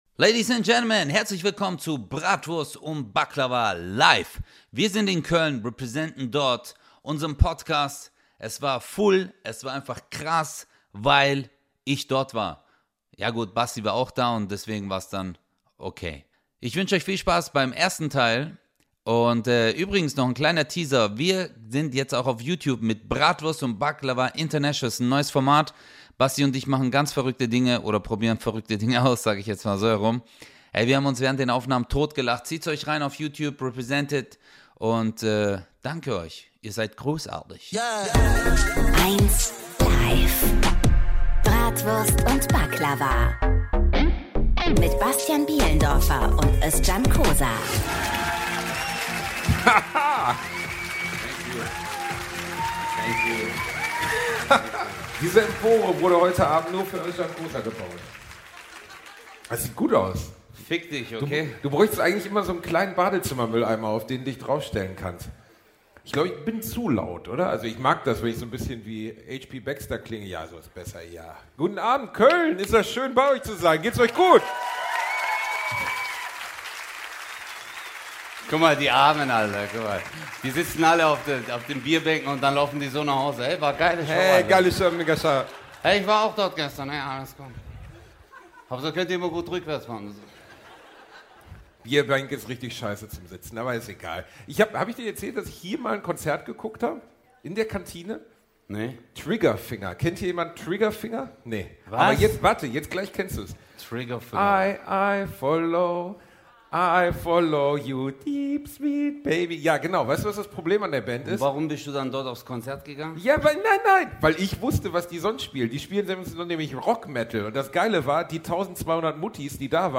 #111 Der Gitarrengott LIVE in Köln 1 ~ Bratwurst und Baklava - mit Özcan Cosar und Bastian Bielendorfer Podcast